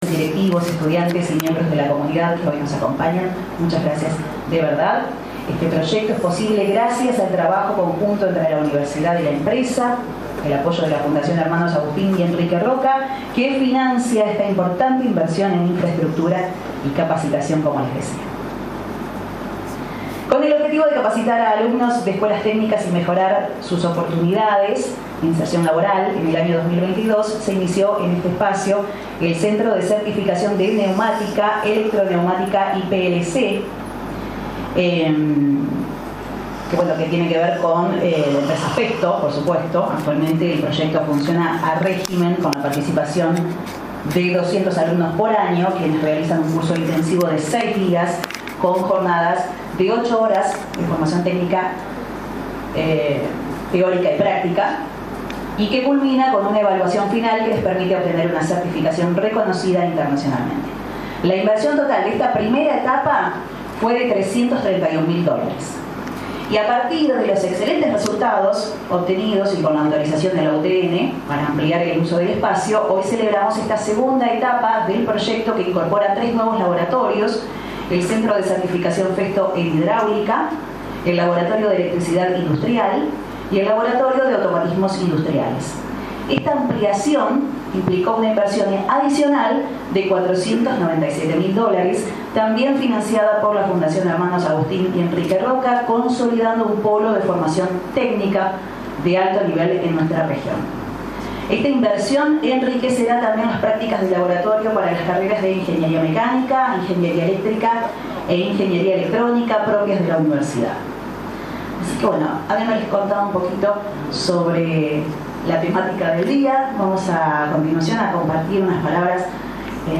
INAUGURACION-UTN.mp3